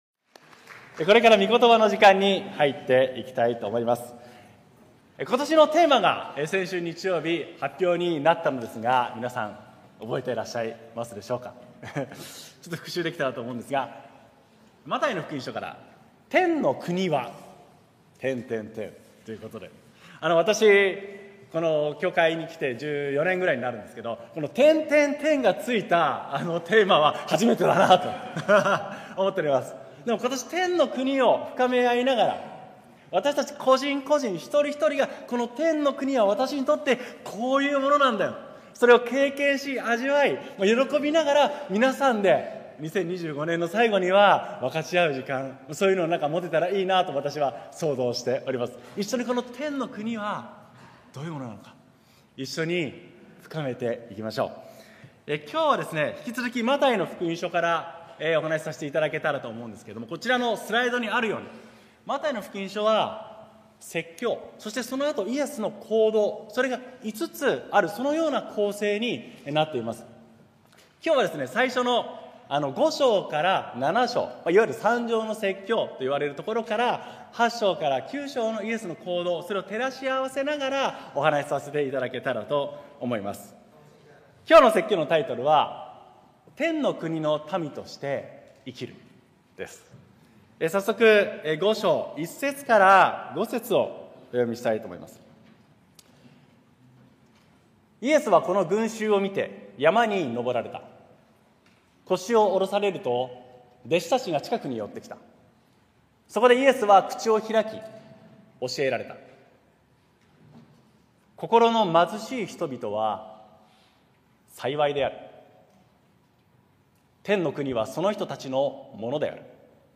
東京キリストの教会 日曜礼拝説教